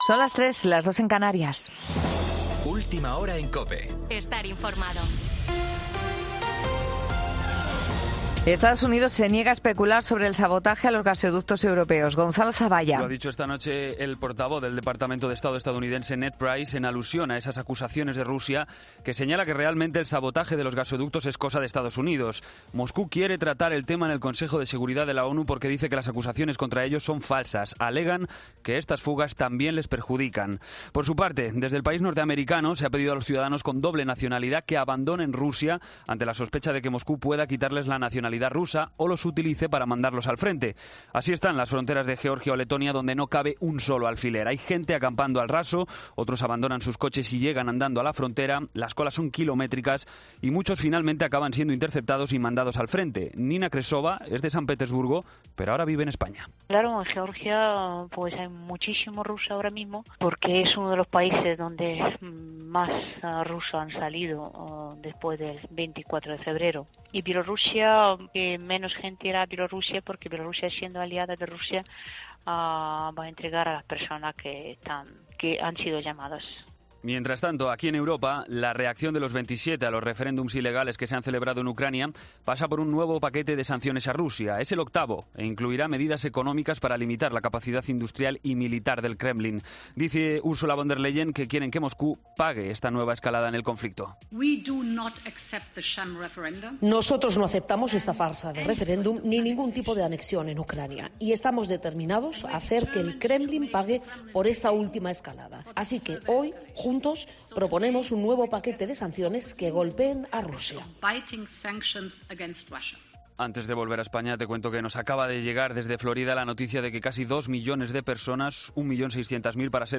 Boletín de noticias COPE del 29 de septiembre a las 03:00 hora
AUDIO: Actualización de noticias Herrera en COPE